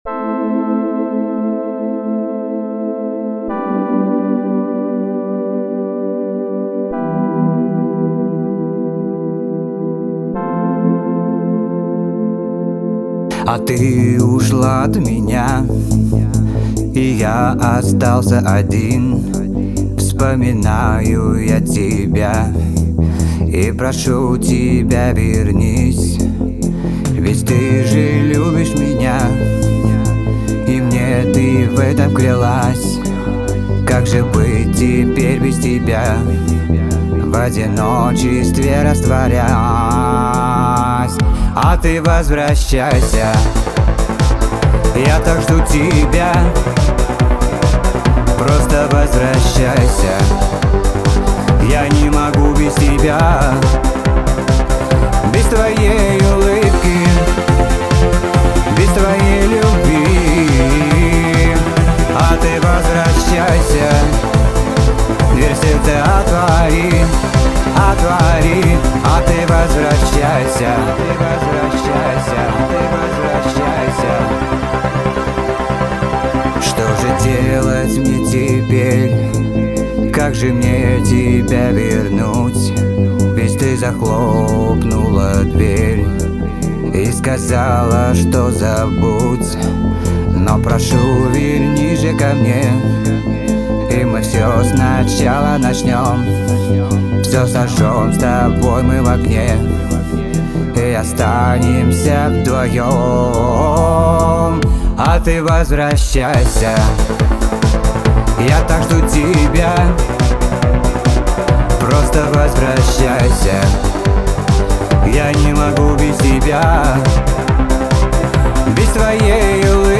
инди-поп музыки 90-х